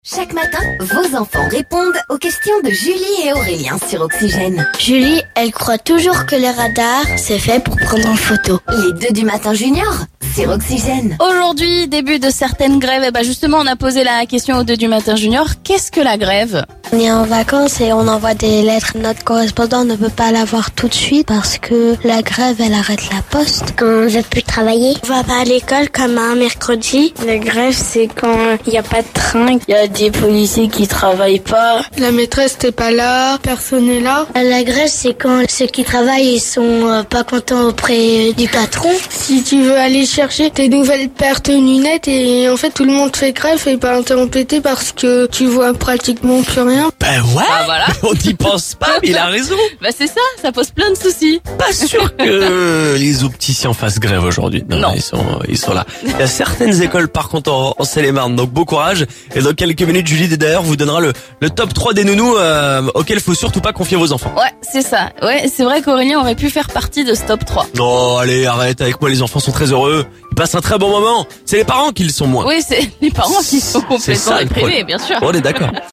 Écouter le podcast Télécharger le podcast Écoutons les enfants Seine-et-Marnais nous expliquer ce qu'est une grève...